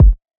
Kicks